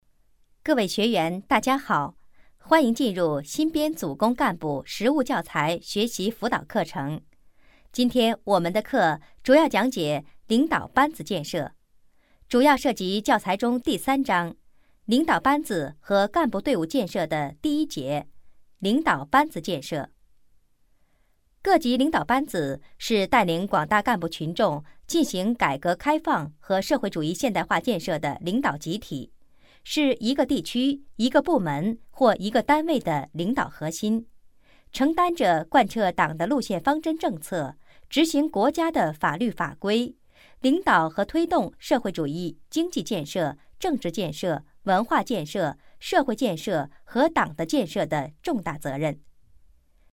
女声配音
课件女国55